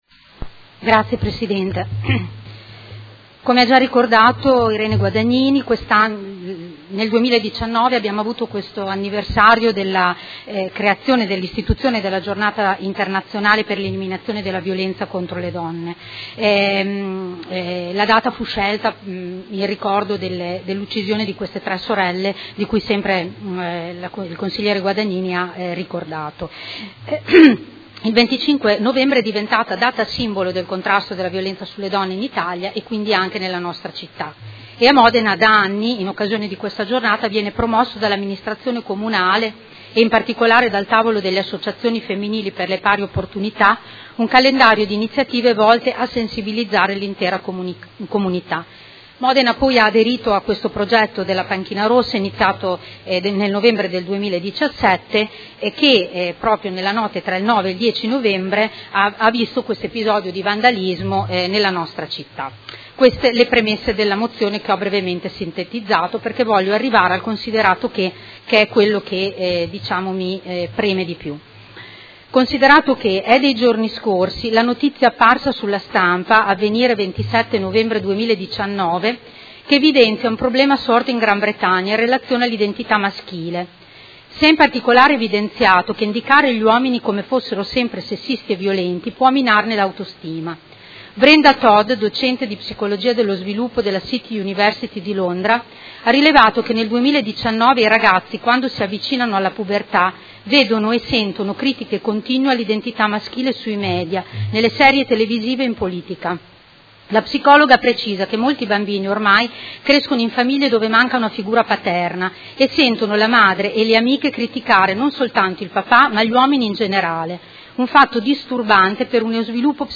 Elisa Rossini — Sito Audio Consiglio Comunale